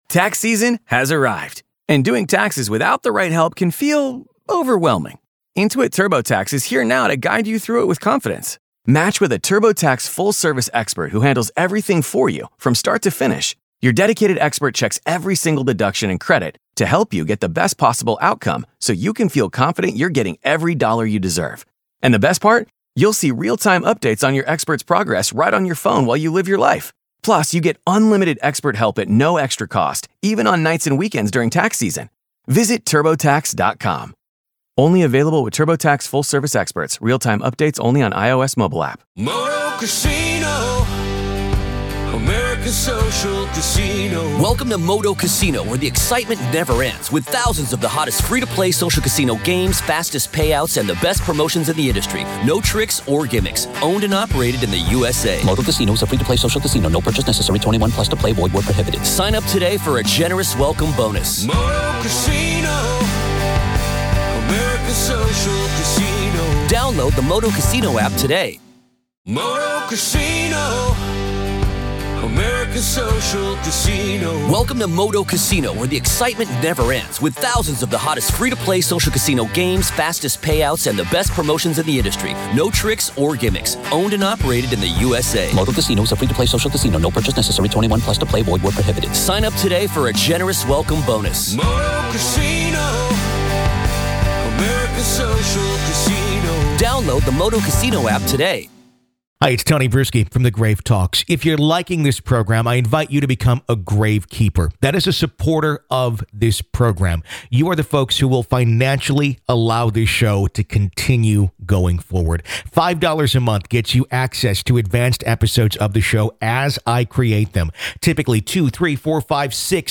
Bothell Hell House | Classic Interview